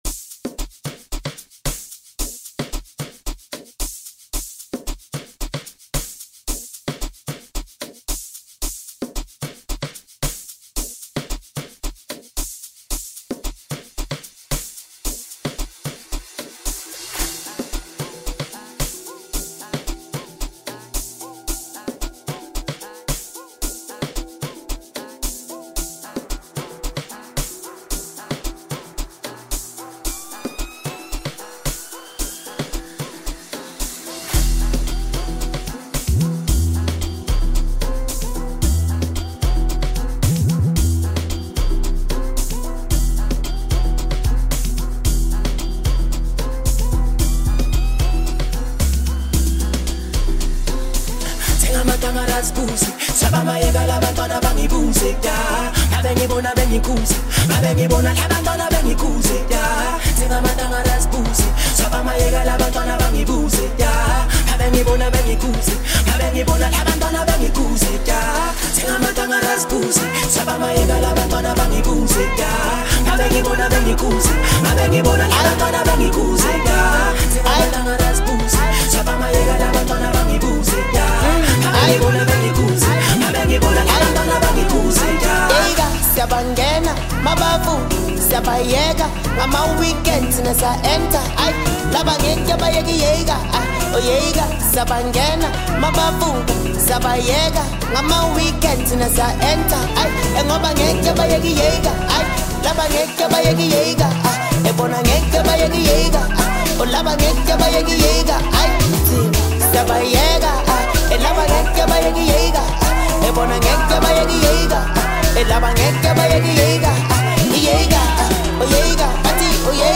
a new piano song